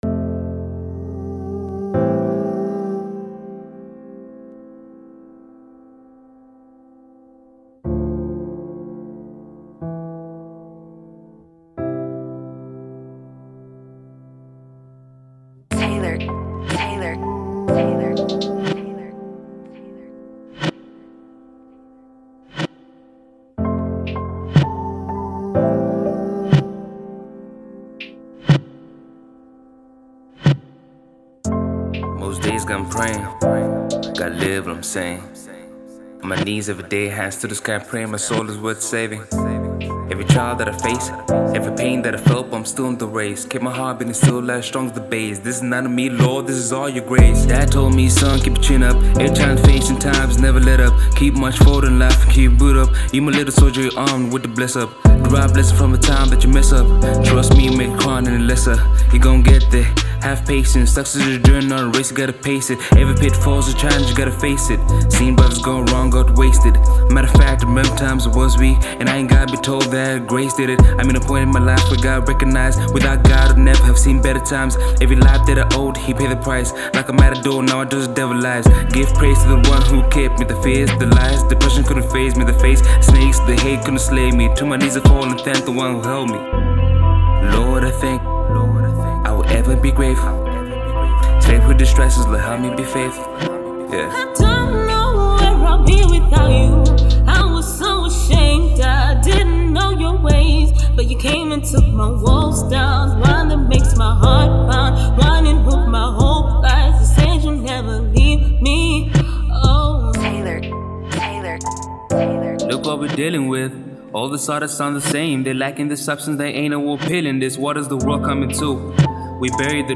gospel Hip hop